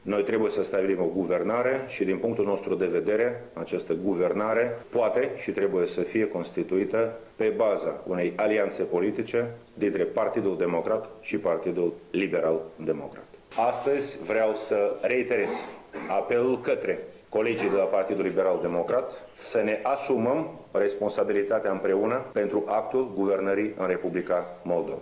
Declarația lui Marian Lupu